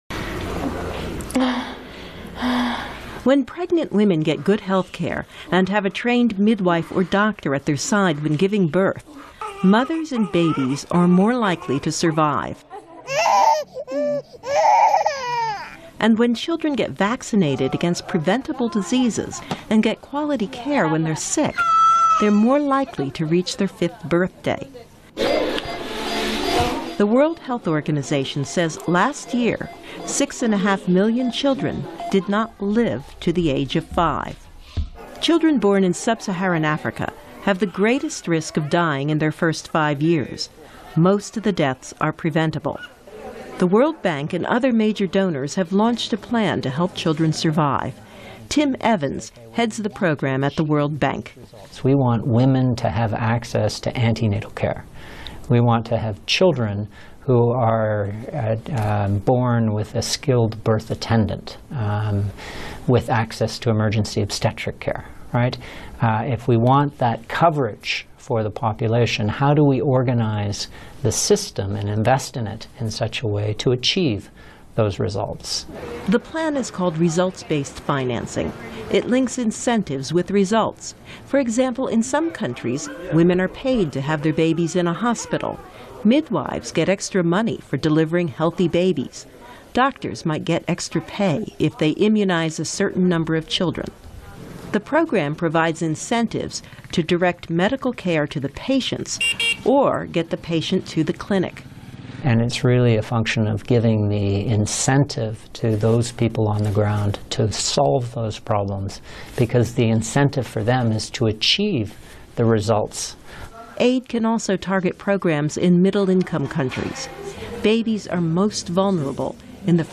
VOA常速英语|新计划帮助发展中国家病患得到更好医疗(VOA视频)